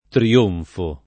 tri-1nfo] s. m. — latinismo ant. triunfo [